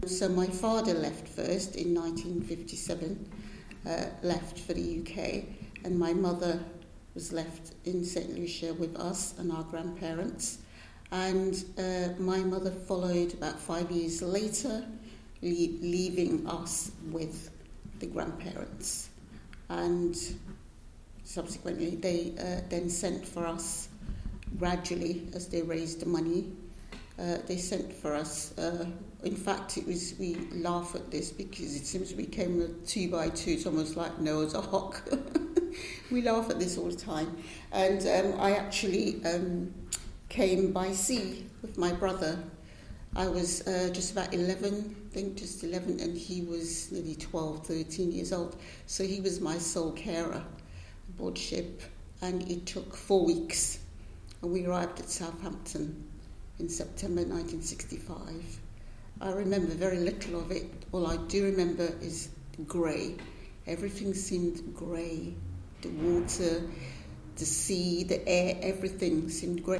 interviewee
This oral history excerpt has been drawn from the three-year AHRC-funded project ‘The Windrush Scandal in a Transnational and Commonwealth Context’.